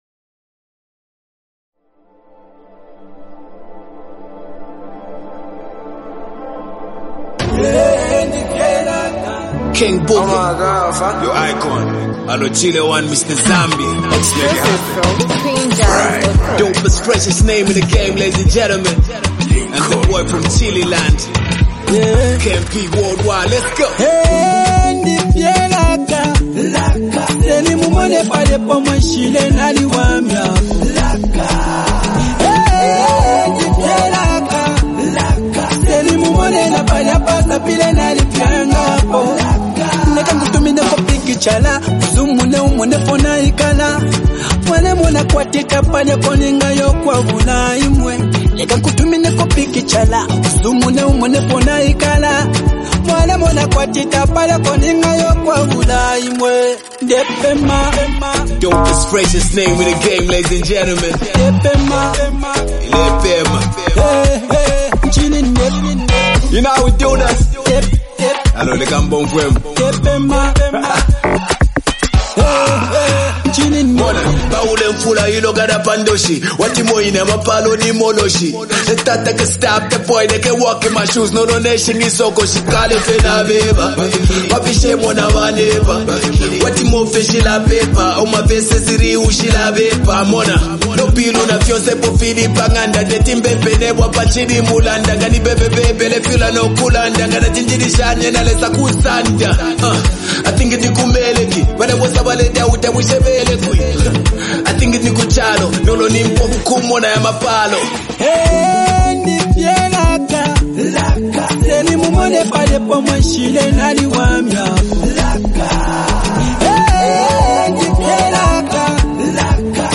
and a blend of hip-hop and melodic emotion